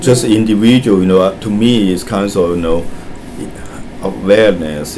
S1 = Taiwanese female S2 = Hong Kong male Context: S2 is talking about what you can do to reduce wastage and pollution.
It is possible that this unexpected use fo the plural kinds has contributed to the misunderstanding, as there seems nothing in particular problematic with the pronunciation.